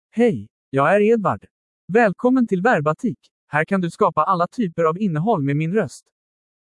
EdwardMale Swedish AI voice
Edward is a male AI voice for Swedish (Sweden).
Voice sample
Listen to Edward's male Swedish voice.
Male
Edward delivers clear pronunciation with authentic Sweden Swedish intonation, making your content sound professionally produced.